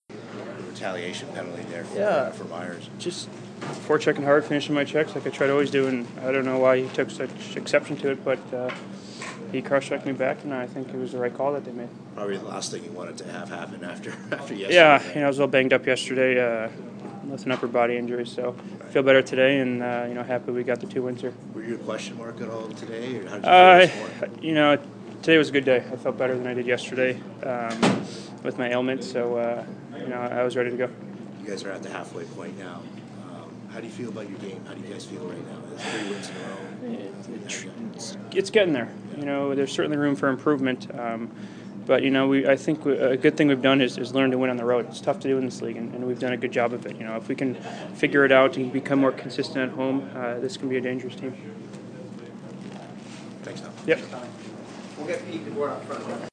Post-game audio from the Sharks dressing room.